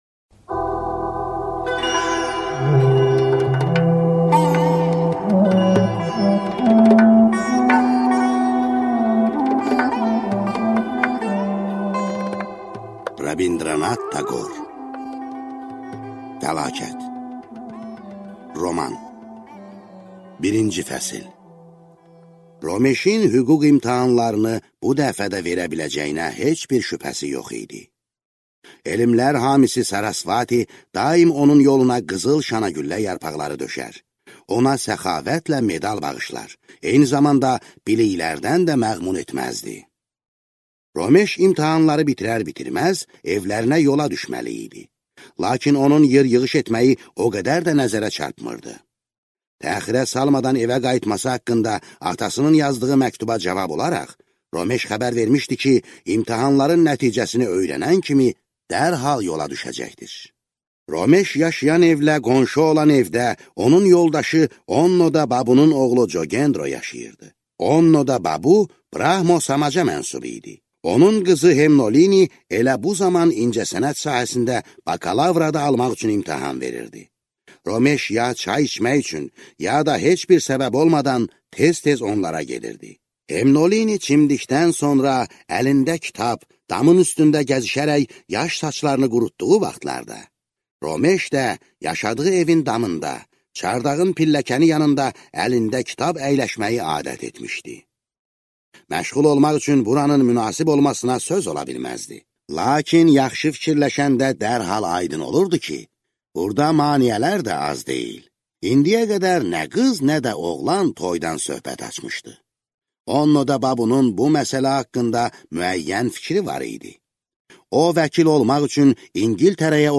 Аудиокнига Fəlakət | Библиотека аудиокниг